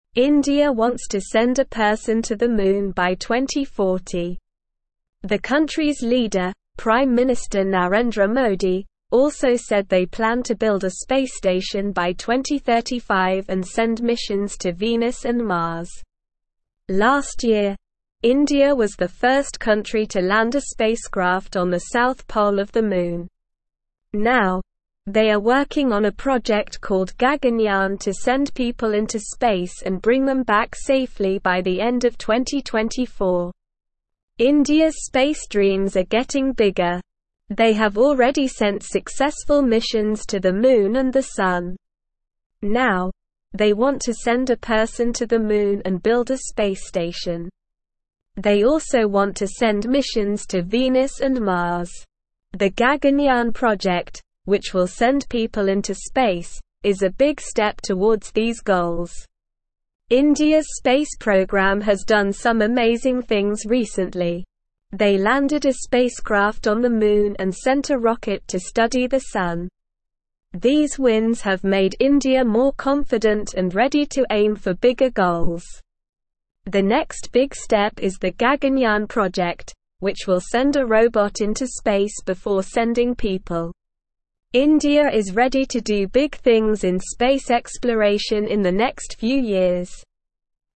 Slow
English-Newsroom-Lower-Intermediate-SLOW-Reading-Indias-Big-Space-Dreams-Moon-Sun-and-More.mp3